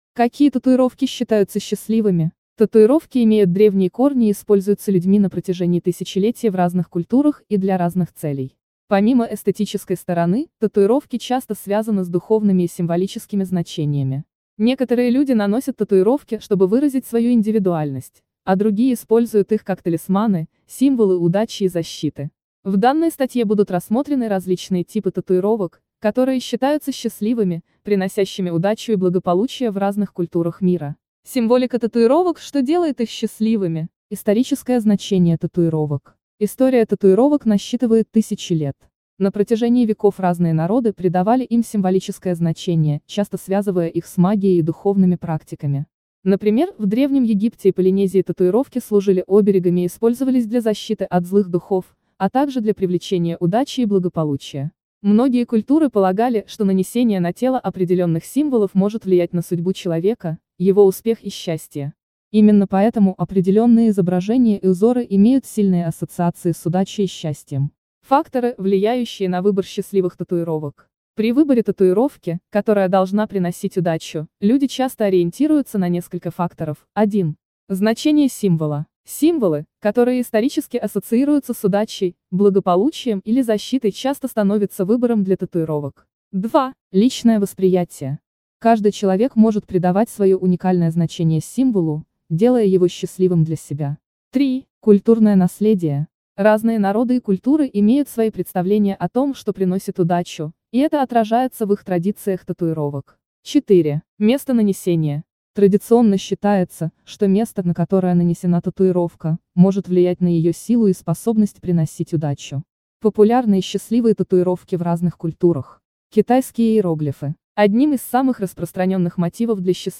Какие татуировки приносят удачу — аудио версия статьи для tatufoto